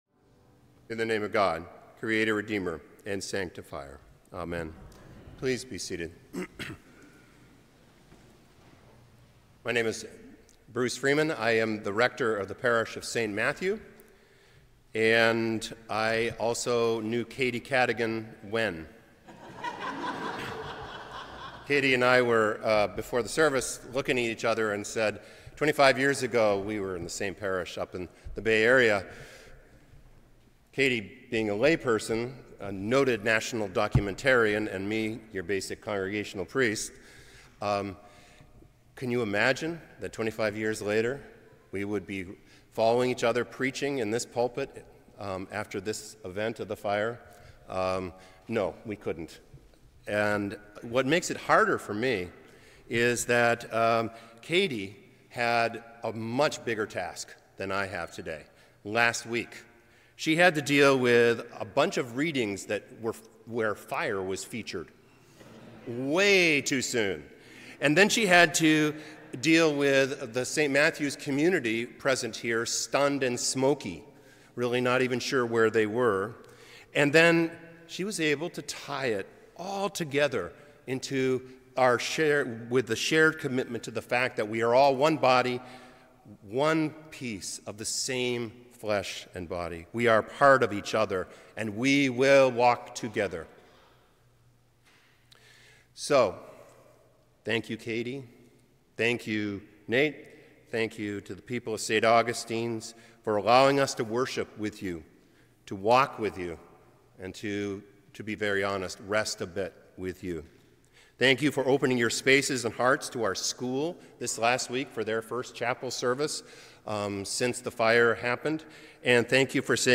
2nd Sunday after Epiphany Image: Palisades fire, 5:24pm the 1st night.
St. Augustine by-the-Sea Sermons